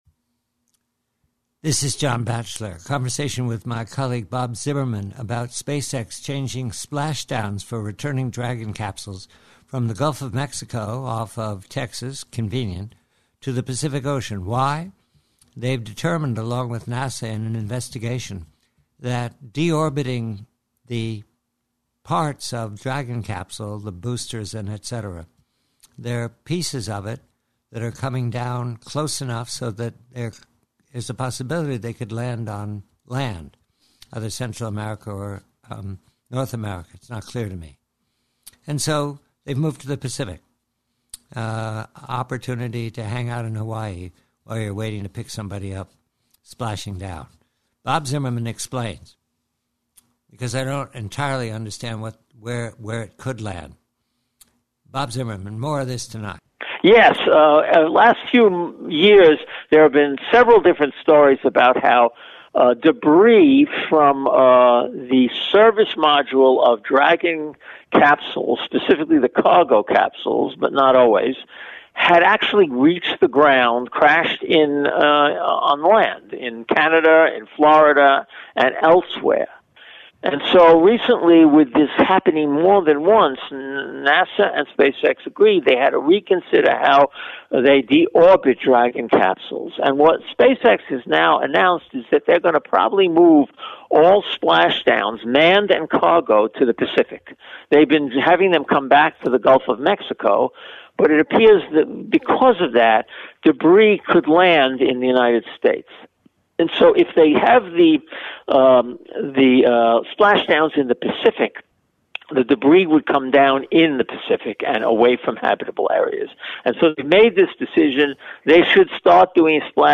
PREVIEW: SPACEX: Conversation